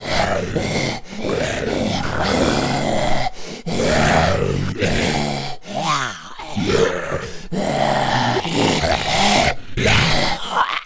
Zombie_03.wav